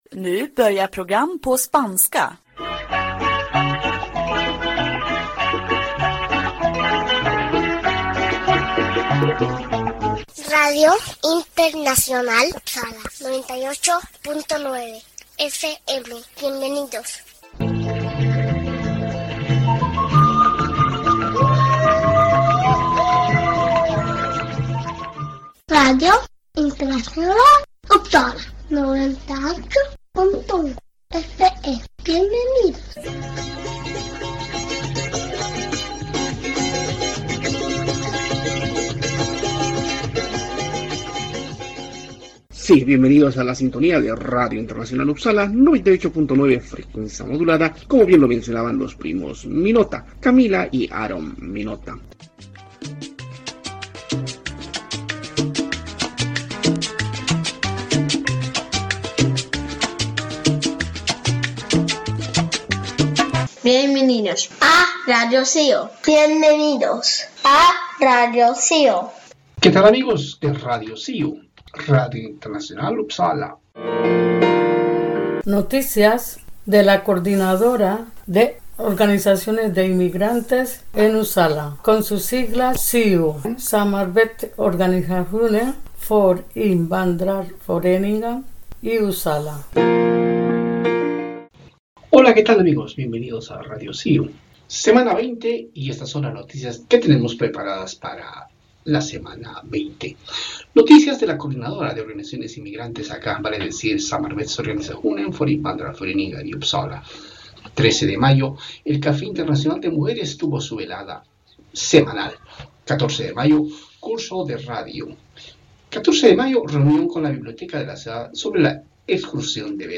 Sí, radio de cercanías en Uppsala se emite domingo a domingo a horas 18:30.